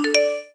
UI_Hint.wav